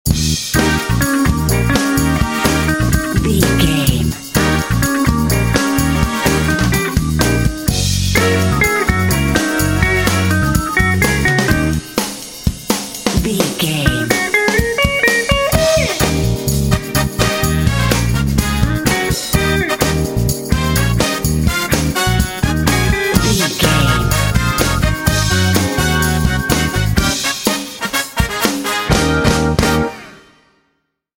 Aeolian/Minor
E♭
funky
happy
bouncy
groovy
bass guitar
drums
electric guitar
electric organ
brass
electric piano
jazz
blues